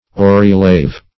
aurilave - definition of aurilave - synonyms, pronunciation, spelling from Free Dictionary
Search Result for " aurilave" : The Collaborative International Dictionary of English v.0.48: Aurilave \Au`ri*lave\, n. [L. auris ear + lavare to wash.]